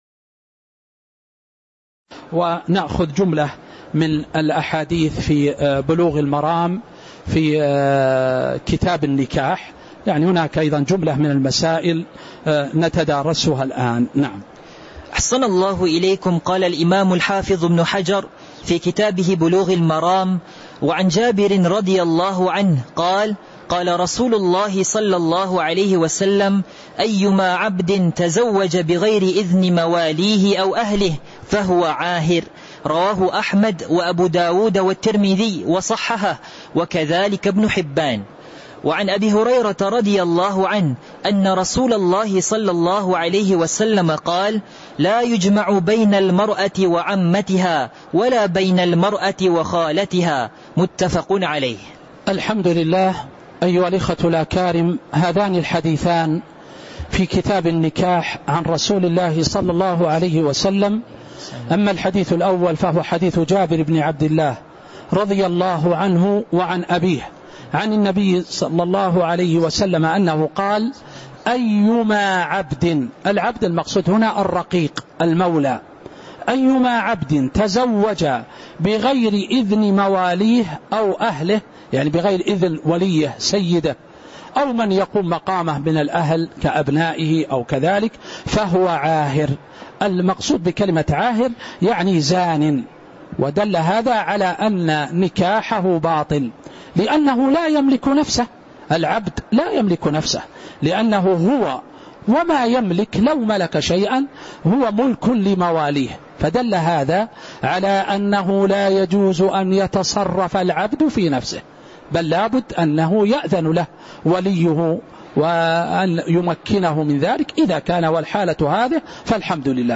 تاريخ النشر ١٨ شعبان ١٤٤٦ هـ المكان: المسجد النبوي الشيخ